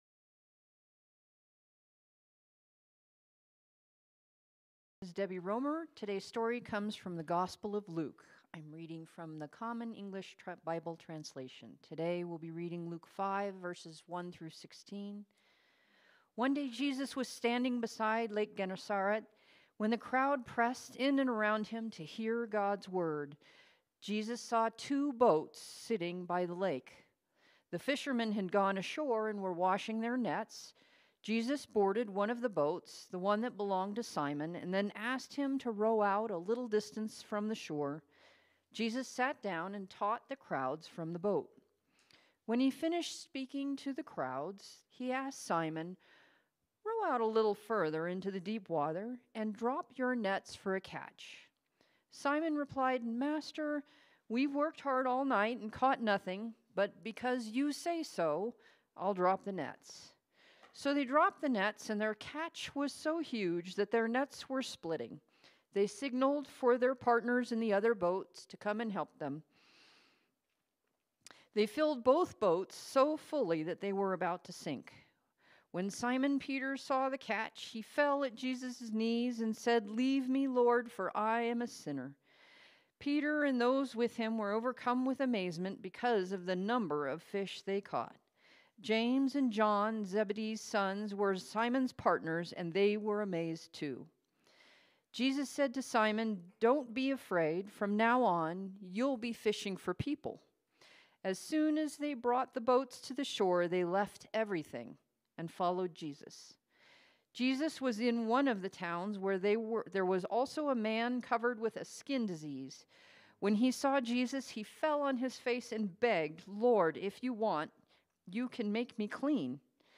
Sermons | United Methodist Church of Evergreen